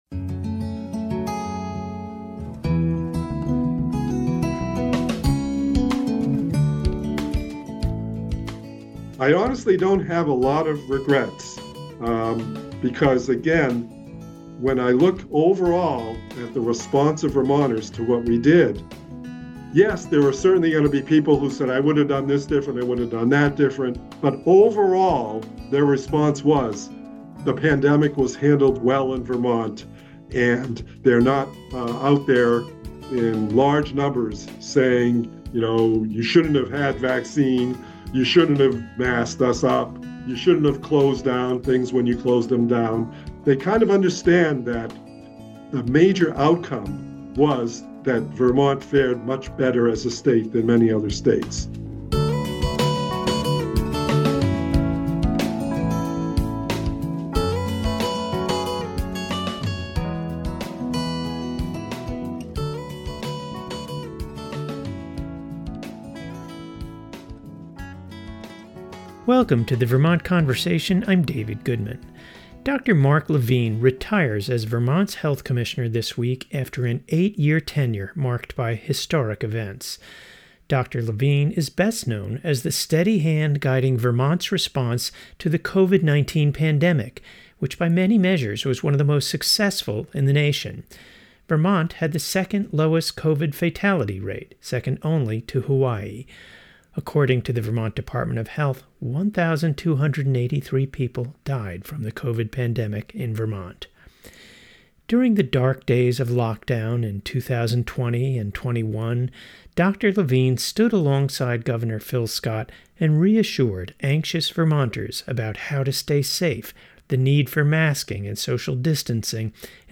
His grandfatherly baritone voice conveyed wisdom and compassion.